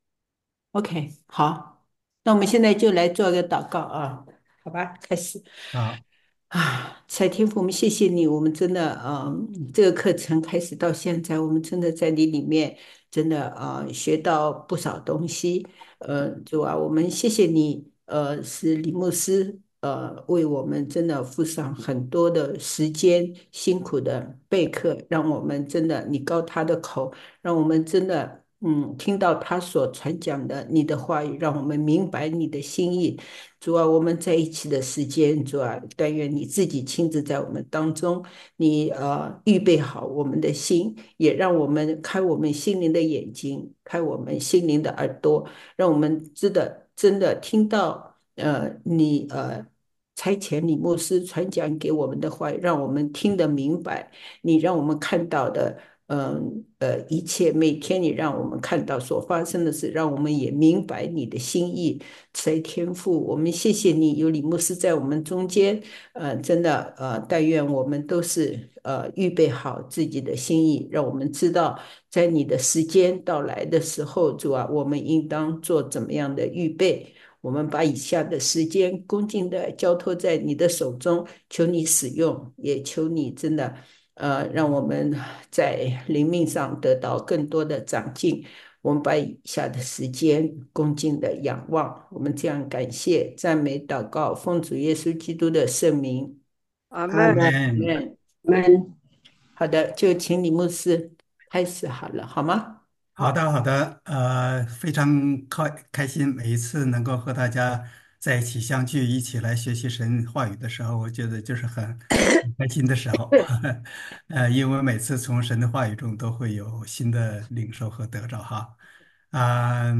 来自讲道系列 "進深查考：“小啟示錄”和《啟示錄》"